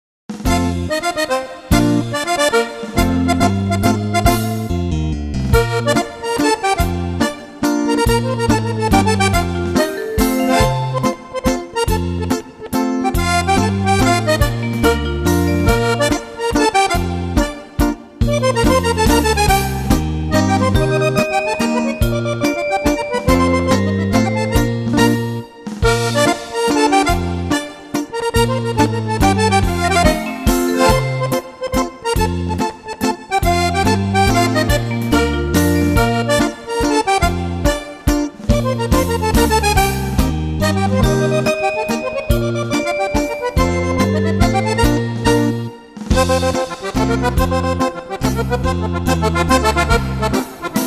mazurka
Fisa